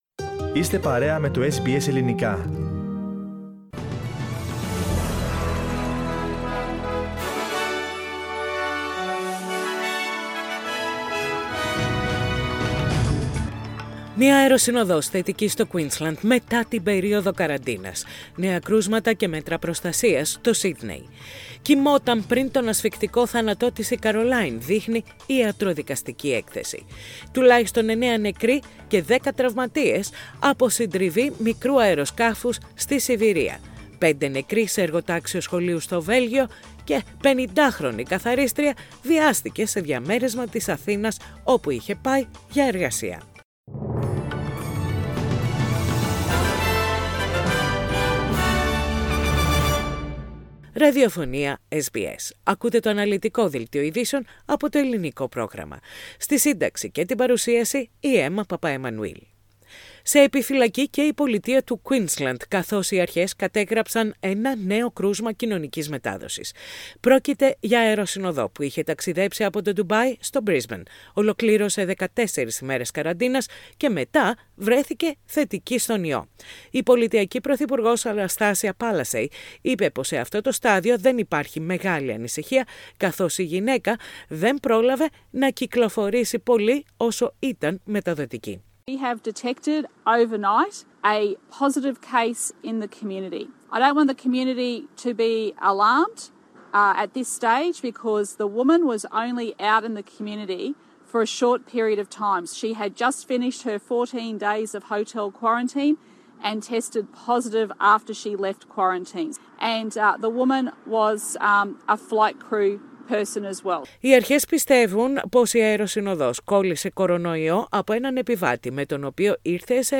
Main news of the day from SBS radio Greek.